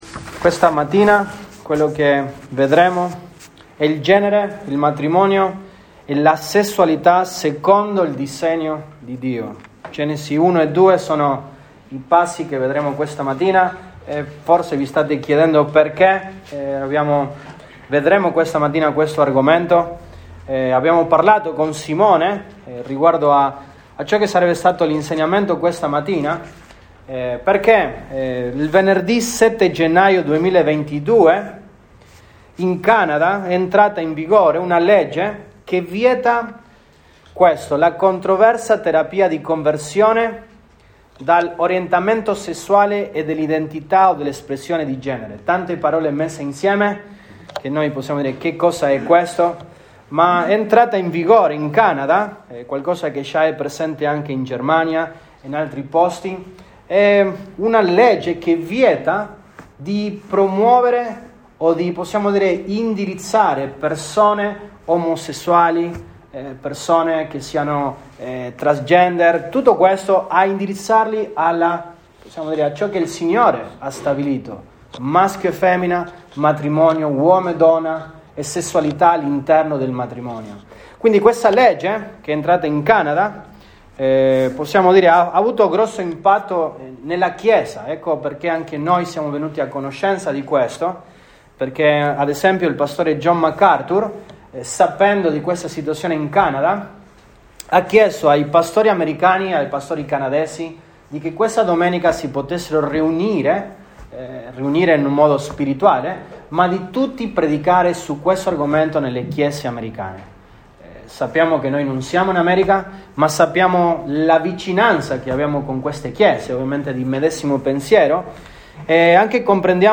Gen 16, 2022 Il genere, il matrimonio e la sessualità MP3 Note Sermoni in questa serie Il genere, il matrimonio e la sessualità.